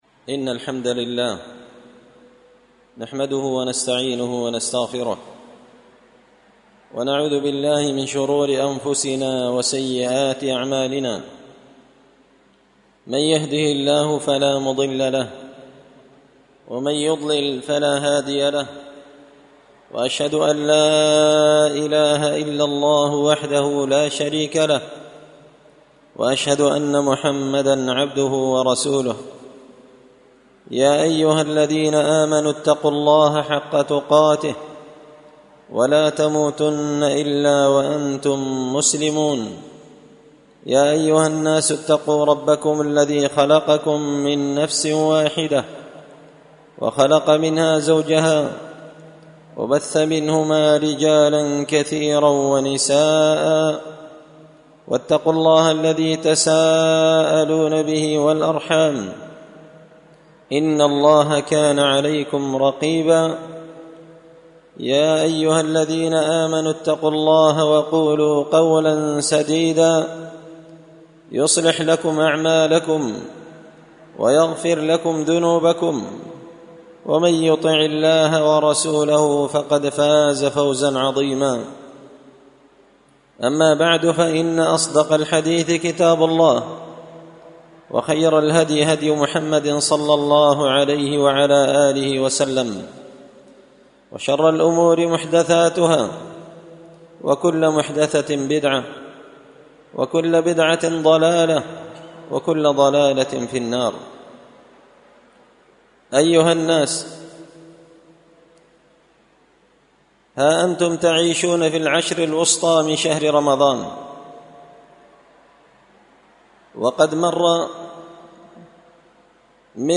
خطبة جمعة بعنوان – الاجتهاد فيما تبقى من رمضان
دار الحديث بمسجد الفرقان ـ قشن ـ المهرة ـ اليمن